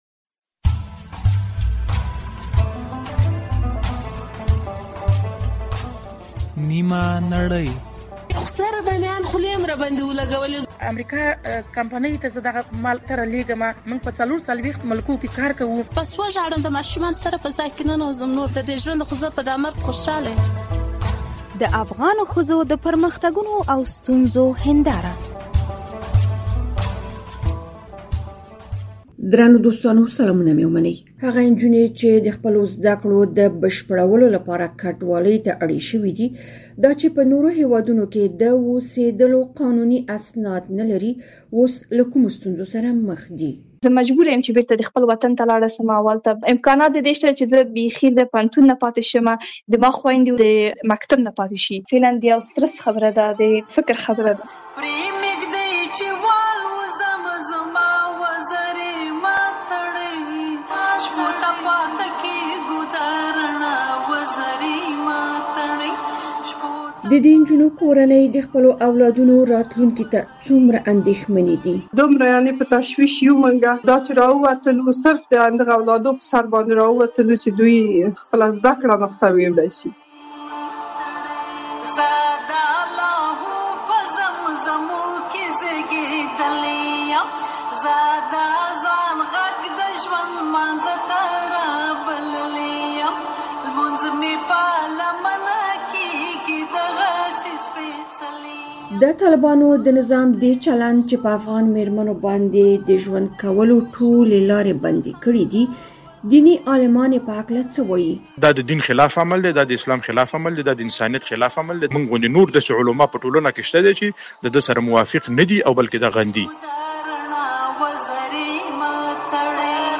د ازادي راډیو په اوونیزې تاندې څانګې خپرونه کې د هغو ځوانانو خبرې اورئ چې وایي، کورنۍ یې د کوژدې پر وخت د دوی انتخاب په پام کې نه نیسي.